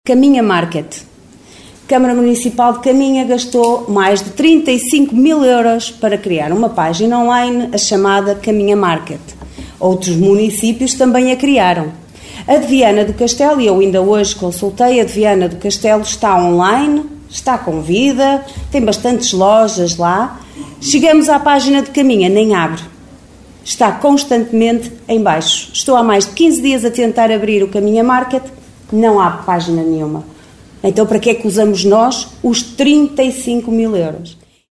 O Caminha Market, plataforma de comércio eletrónico lançada em 2021 em parceria com a Associação Empresarial de Viana do Castelo (AEDVC), na qual a Câmara de Caminha gastou mais de 35 mil euros, está constantemente em baixo, alertou Liliana Silva da Coligação O Concelho em Primeiro (OCP) na última reunião camarária de 5 de março.